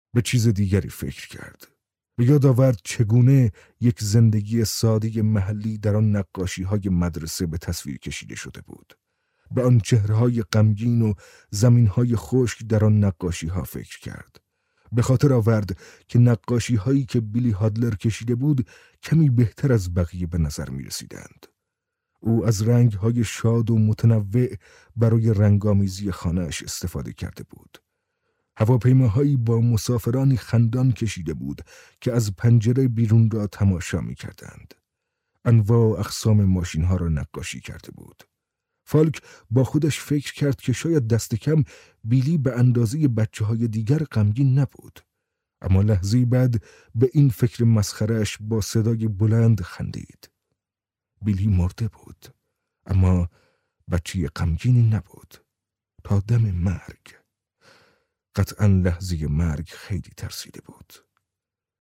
Male
Senior
Audio-Book